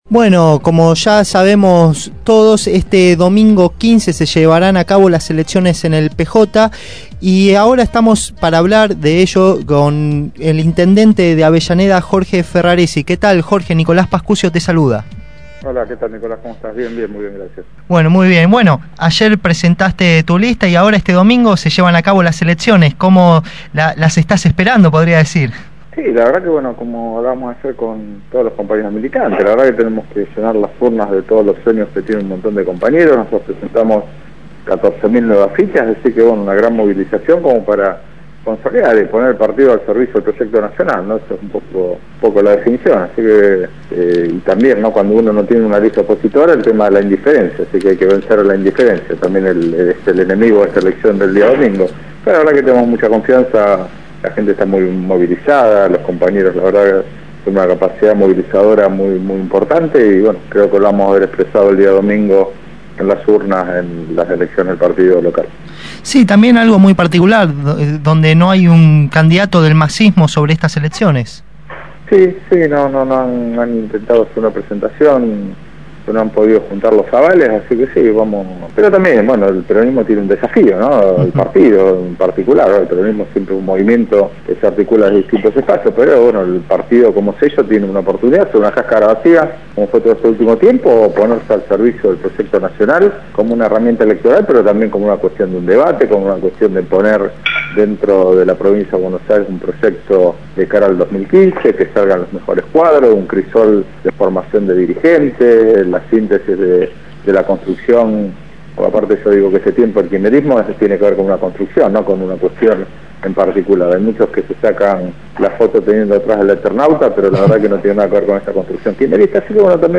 Entrevista-a-Jorge-Ferraresi-Intendente-de-Avellaneda.mp3